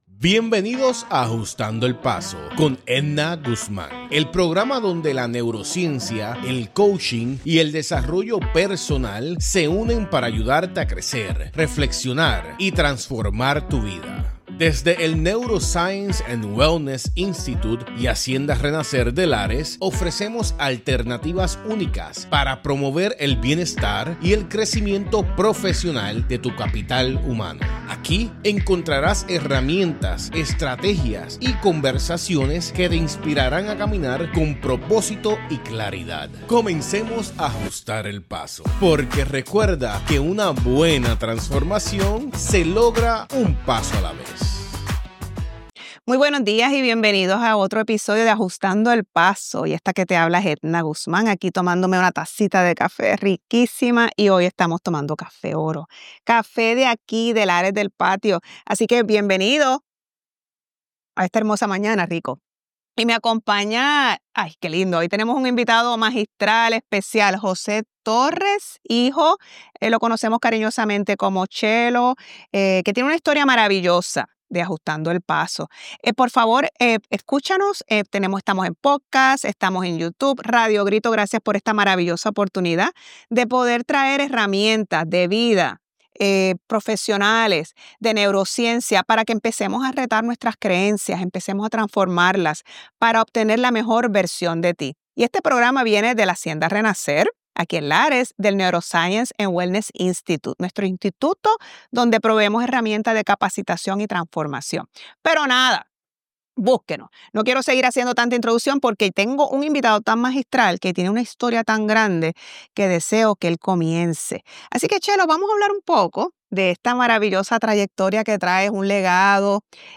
En este episodio entrevistamos